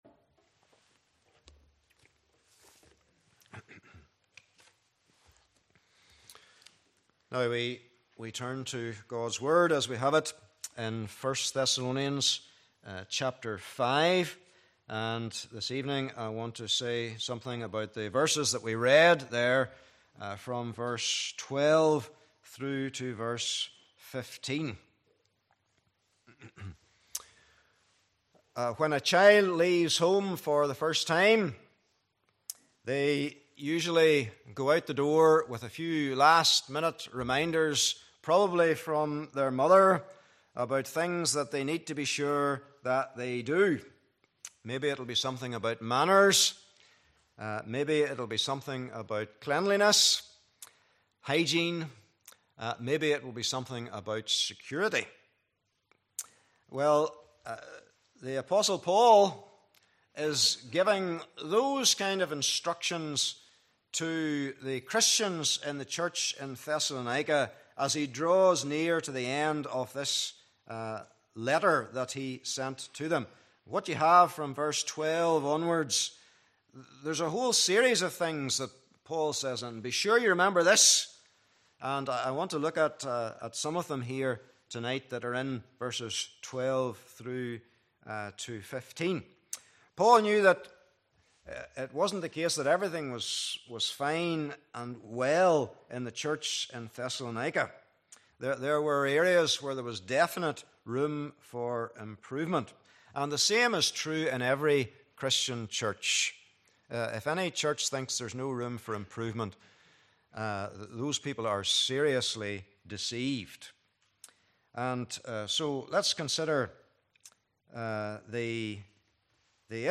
Passage: 1 Thessalonians 5:12-15 Service Type: Evening Service